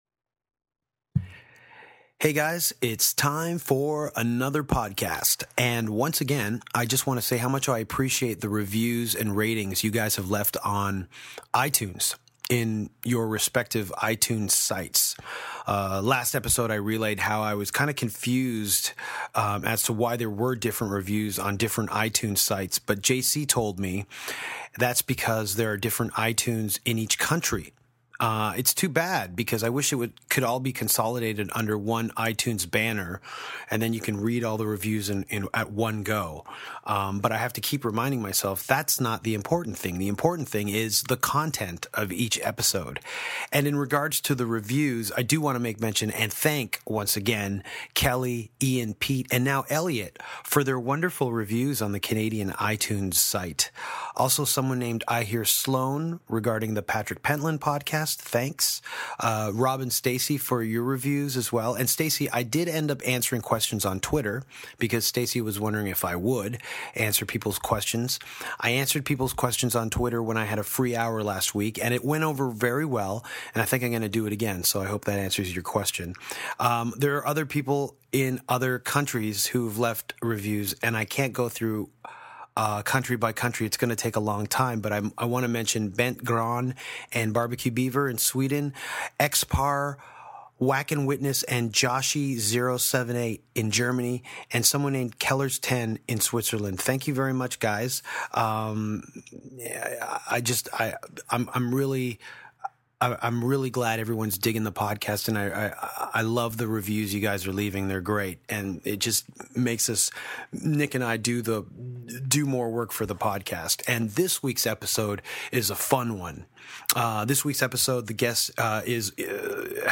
Wade McNeil, singer of Gallows & guitarist of Alexisonfire, sits down with Danko and Nick to talk about The Dead Coast, when Wade met Danko, Fucked Up, Jandek, The Frogs and the transition to Gallows from Alexisonfire.